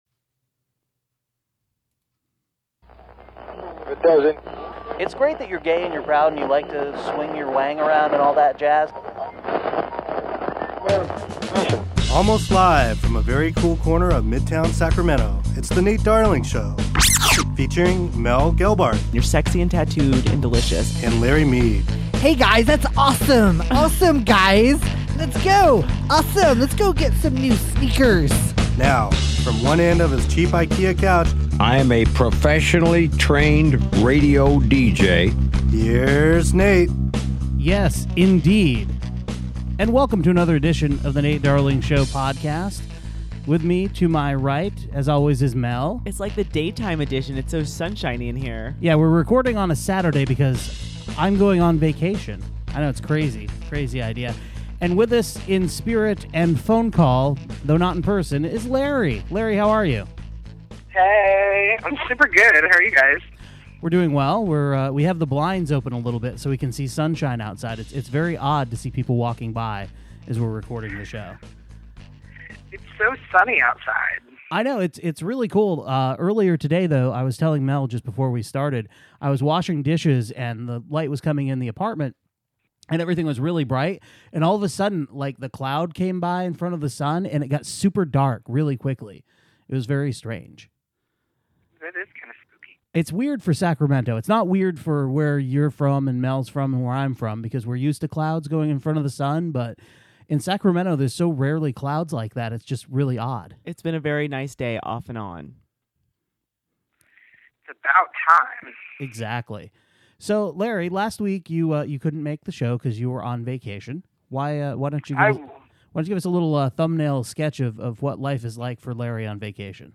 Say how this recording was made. joins us on the phone…from WORK